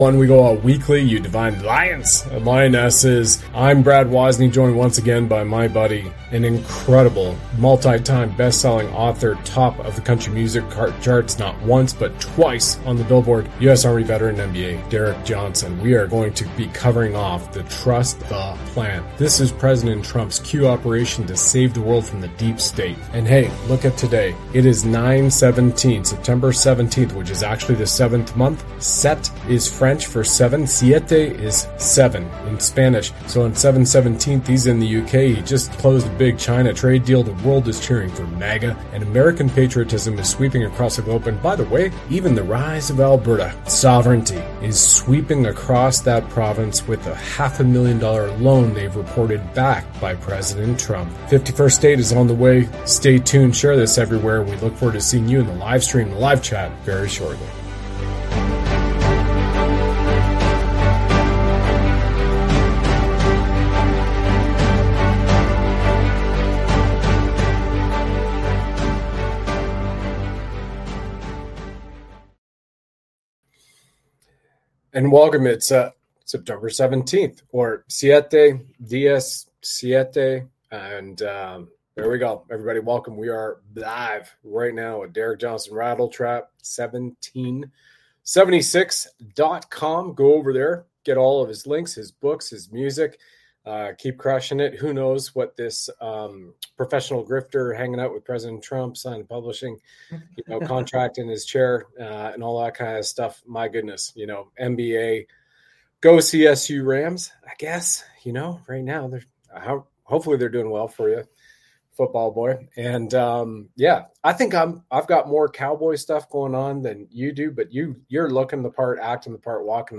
They discuss upcoming trials for treason and emphasize justice's arrival. The conversation highlights the importance of unity and truth, urging listeners to share their thoughts and boost visibility on platforms like Rumble.